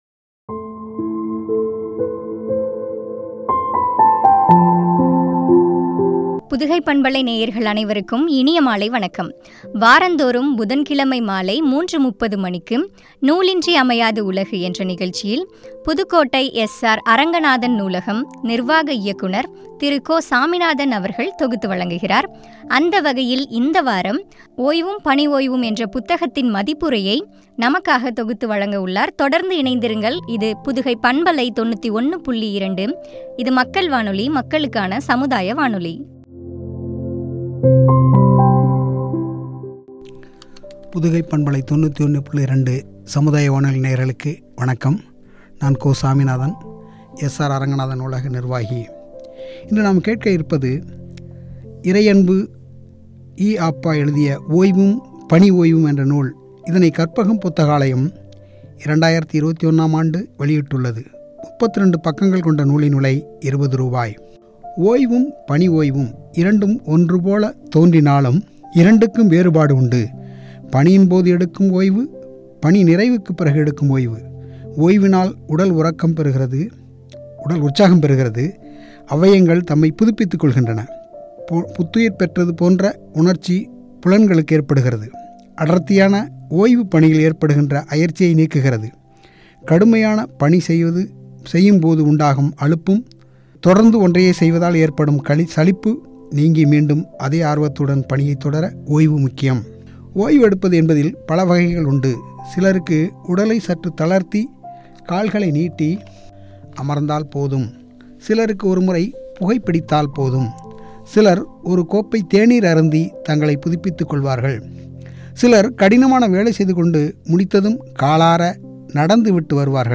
“ஓய்வும் பணி ஓய்வும்” புத்தக மதிப்புரை (பகுதி -35)